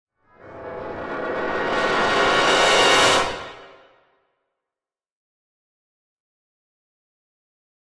Brass Section, Tension Crescendo - "Danger Approach", Type 1